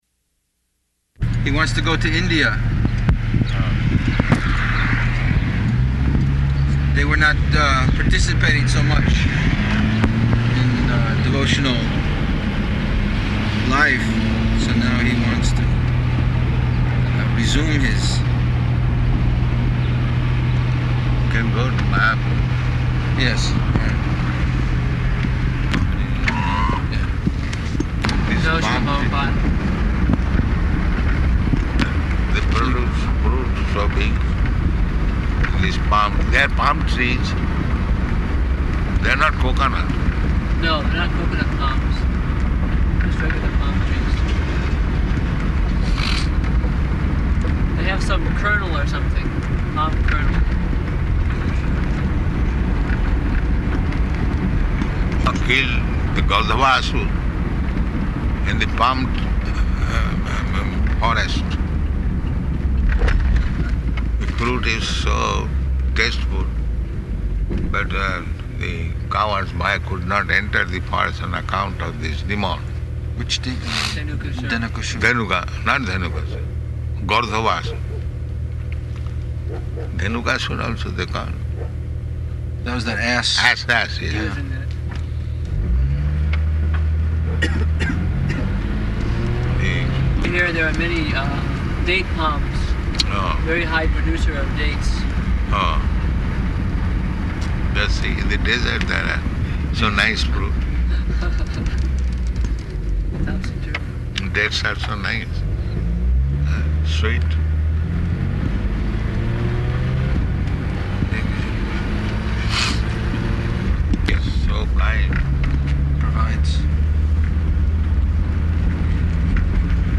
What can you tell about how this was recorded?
June 23rd 1975 Location: Los Angeles Audio file